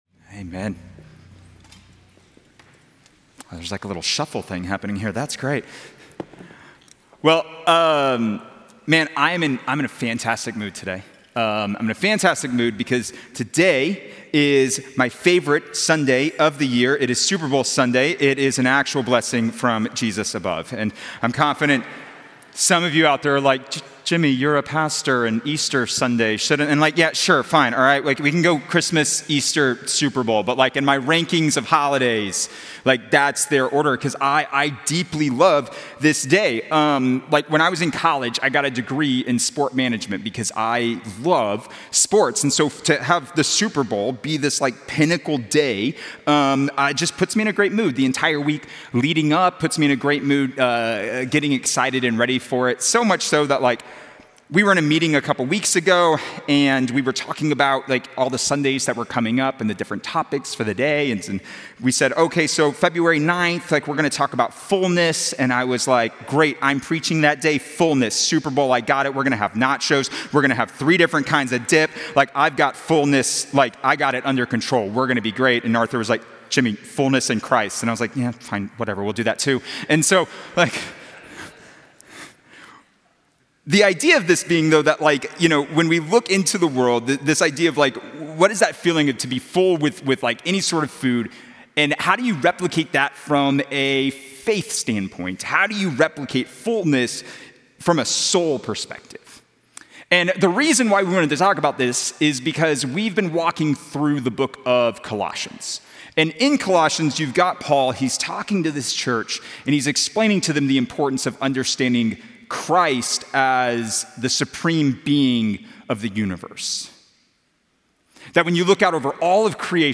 A message from the series "Dear Church."